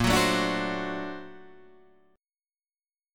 A#+M9 chord